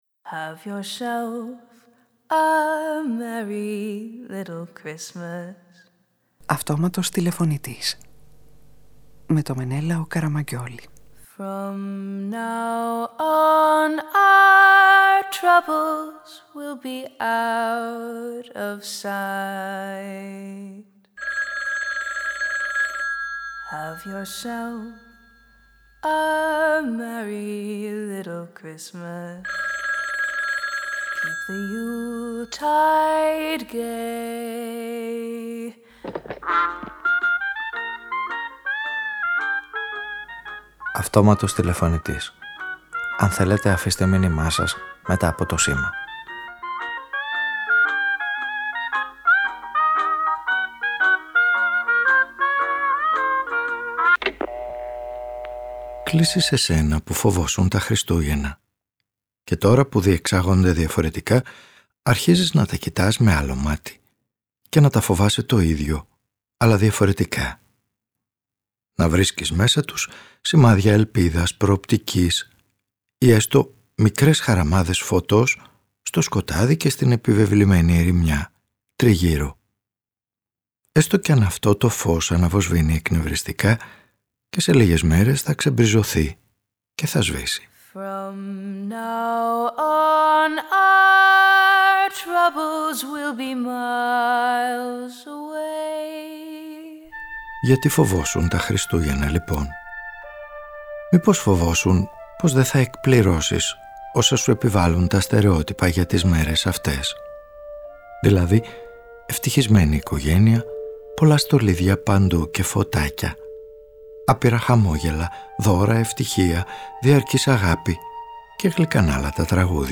Ο ήρωας της σημερινής ραδιοφωνικής ταινίας πάντα φοβόταν τα Χριστούγεννα αλλά, όταν νιώθει να του περιορίζουν τη γιορτή, τσαντίζεται και αγανακτεί: μόνη λύση είναι να κατανοήσει τα πλεονεκτήματα ενός εορτασμού που υπονομεύει όλα τα στερεότυπα και του δίνει τη δυνατότητα να γιορτάσει τη ζωή όπως του αρέσει καθώς ο περιορισμός τον απαλλάσει απ΄όσα του επιβάλονταν εξαναγκαστικά κάθε χρόνο τέτοιες μέρες.